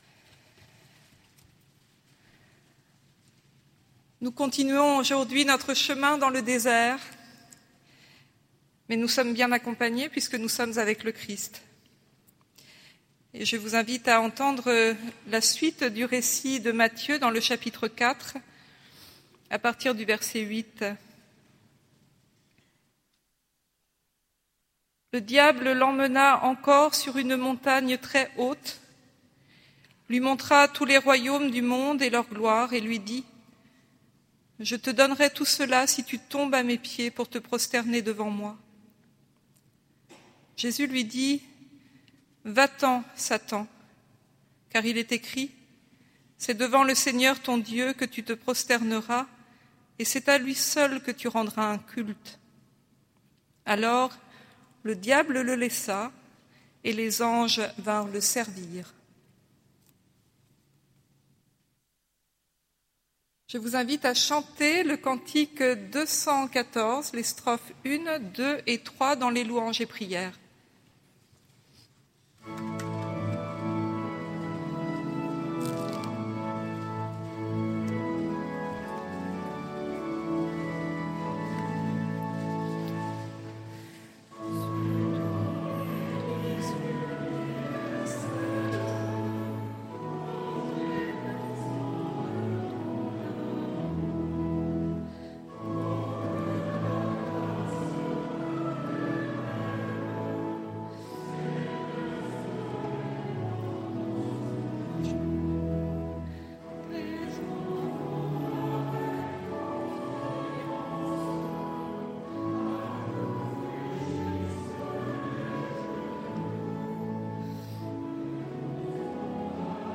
Culte à l’Oratoire du Louvre